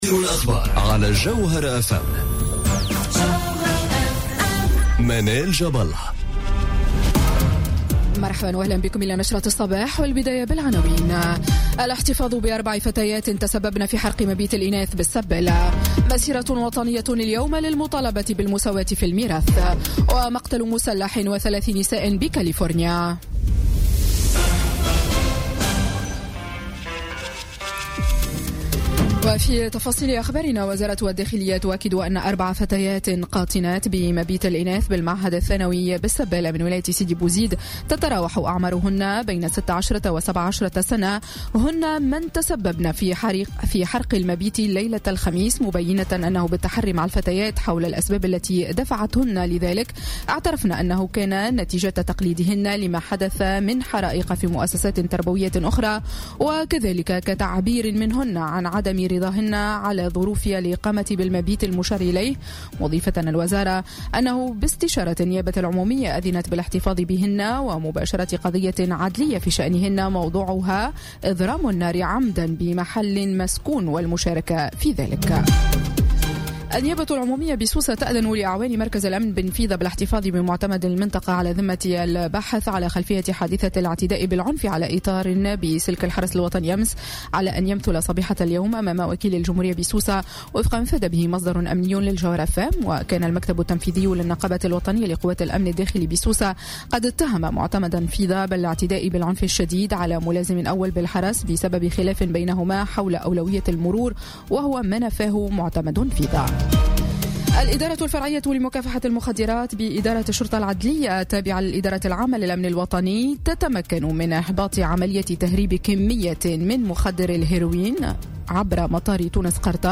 نشرة أخبار السابعة صباحا ليوم السبت 10 مارس 2018